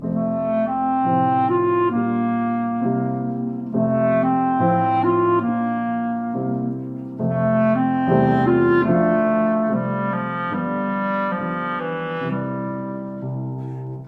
clarinet
harp
piano.